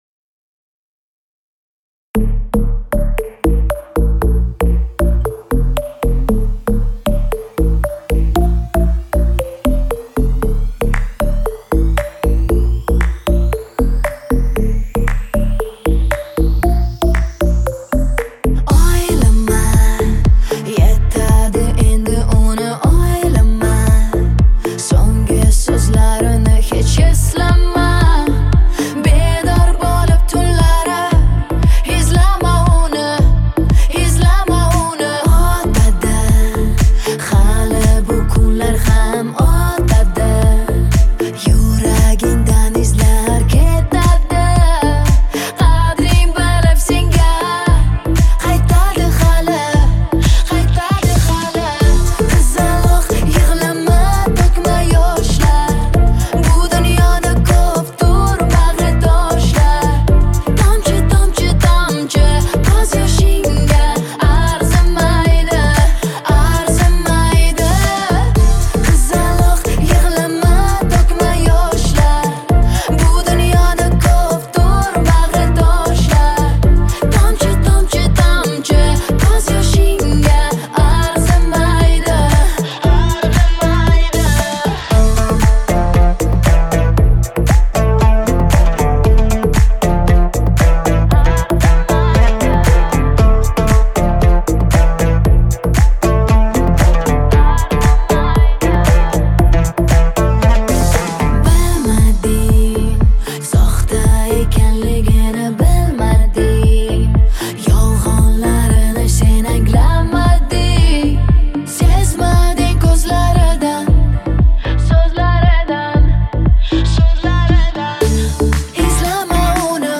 ее голос наполнен нежностью и искренностью